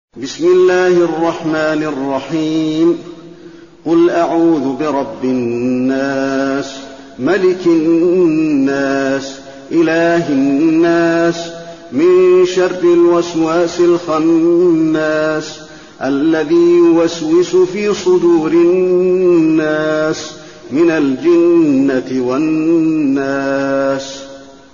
المكان: المسجد النبوي الناس